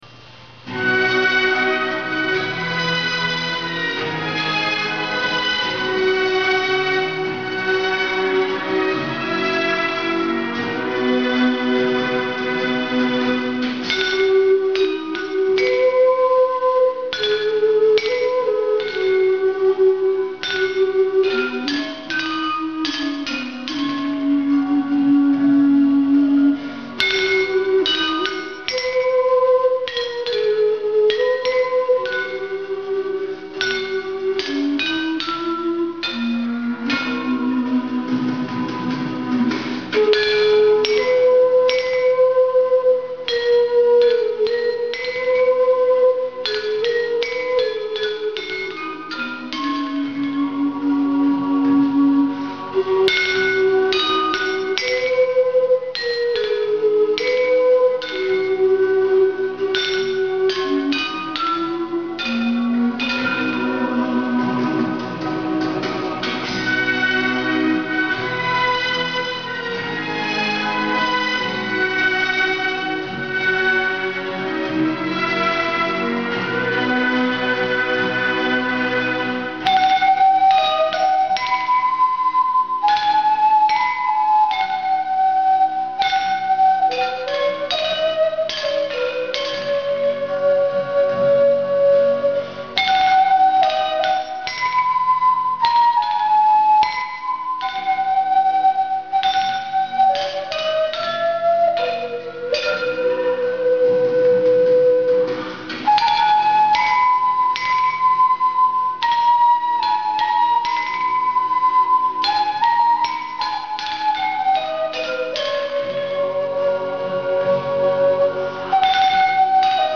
网站首页 乐器论坛 高级搜索 TAG标签 在线留言 下载频道 资源名称： 埙与石磬 温馨提示：欢迎免费下载《 埙与石磬 》，点击上方 [ 资源名称 ] 将返回资源详细介绍页面！
0060_埙与石磬.mp3